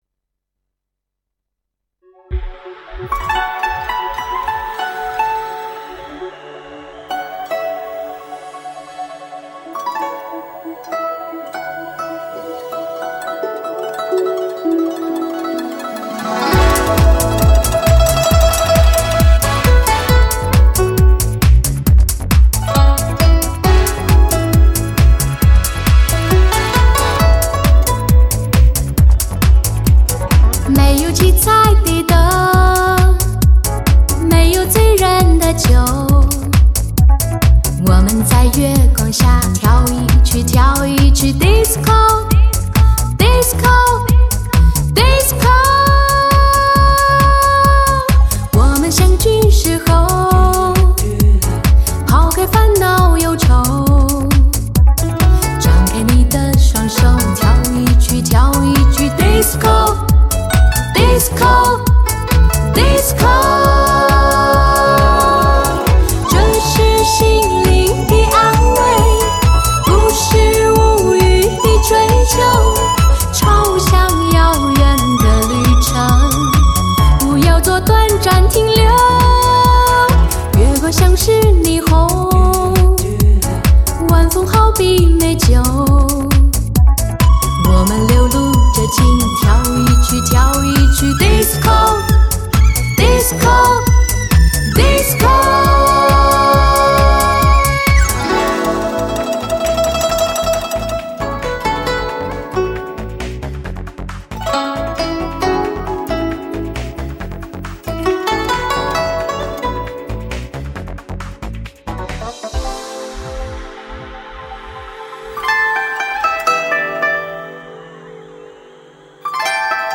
多变节奏+绝美民乐+实力群星+串烧华语金曲
百变动感节奏 + 绝美魅力民乐 + 发烧实力群星